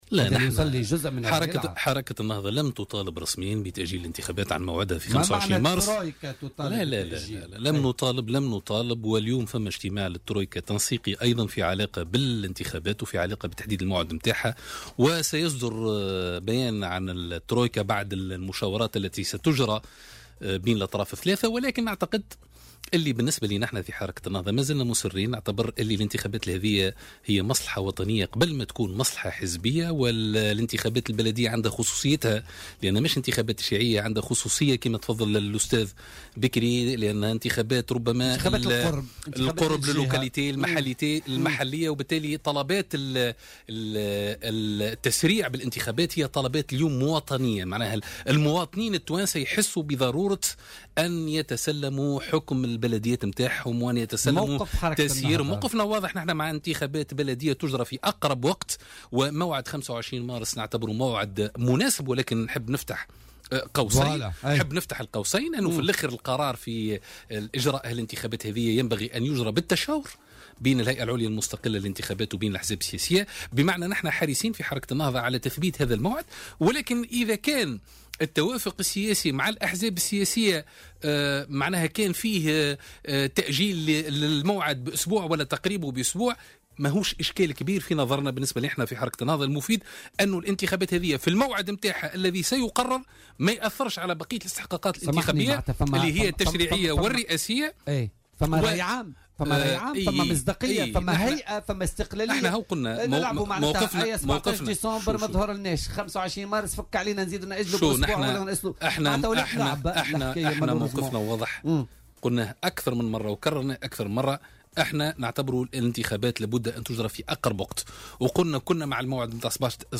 وأوضح الخميري، ضيف برنامج "بوليتيكا" اليوم أن الحركة مازالت مصرّة على إجراءات هذه الانتخابات في أقرب وقت، لكن القرار الأخير ينبغي أن يُتخذ بالتشاور بين الهيئة العليا المستقلة للانتخابات والأحزاب السياسية.